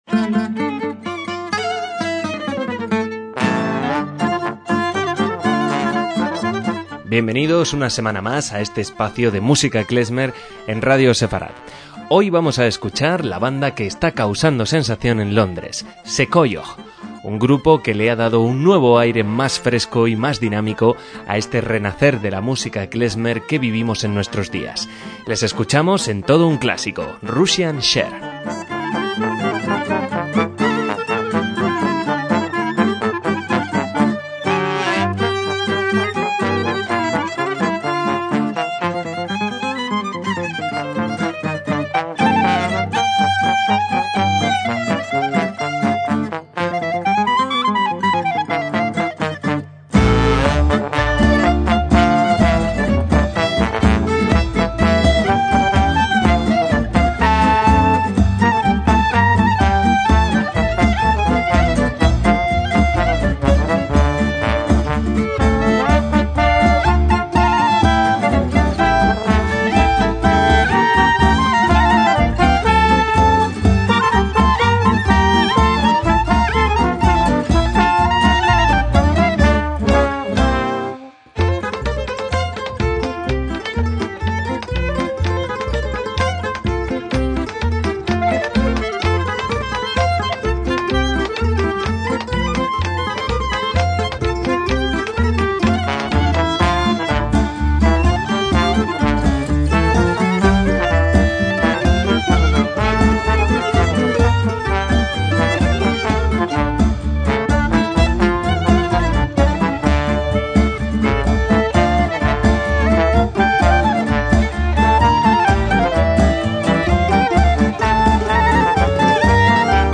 MÚSICA KLEZMER
una banda de música klezmer y tradicional de los Balcanes